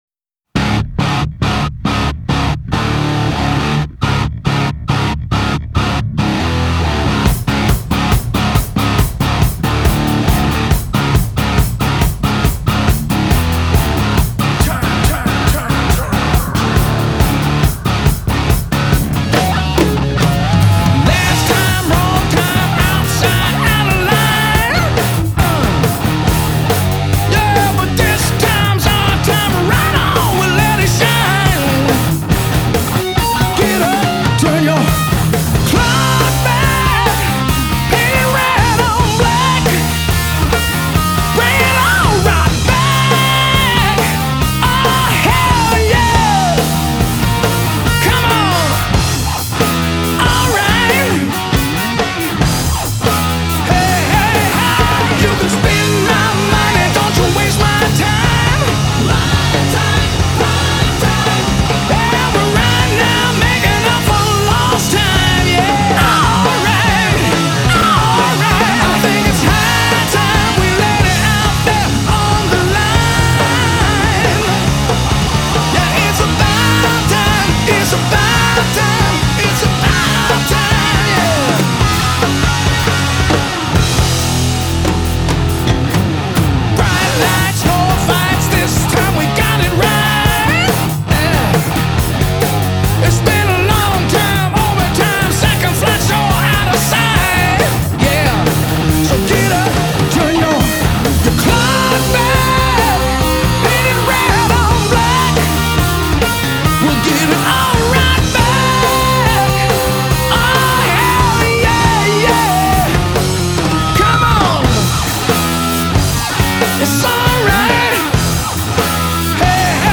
a raw demonstration of the band's new sound